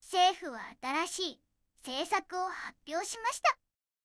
referenceの音声と書き起こしを入力して、Targetのテキストを生成してみました。結構いい感じですが、起伏がオーバーになることが多いのが少し気になりました。
色々パラメータ変えて出力を見てみました。全部同じじゃないですか！